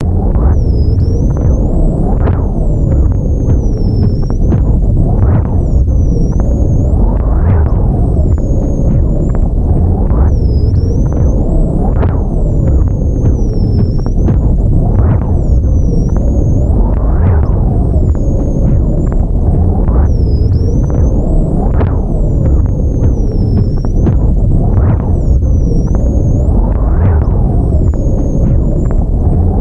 卷到卷的磁带操纵/噪音循环 " 尖叫声束缚者
描述：从磁带操作的哇哇声和尖叫声录制在1/4"磁带和物理循环的
Tag: 尖叫 胶带环 胶带操纵